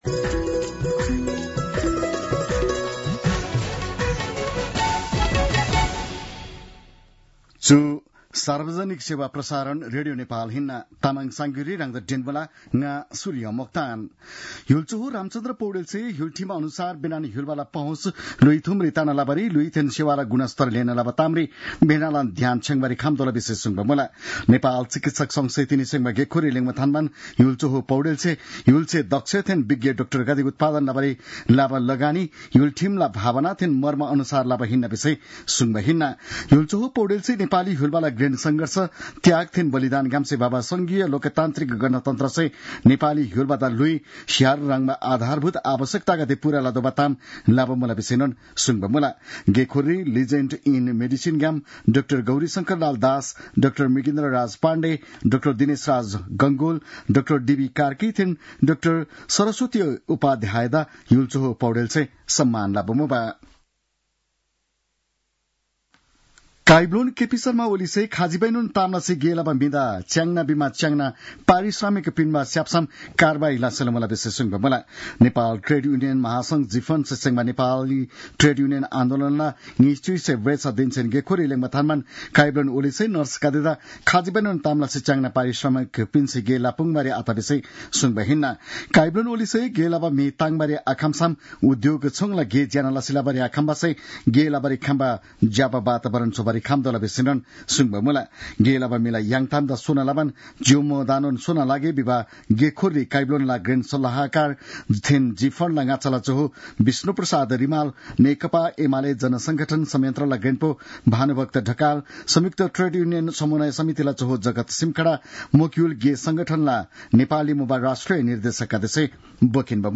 तामाङ भाषाको समाचार : २१ फागुन , २०८१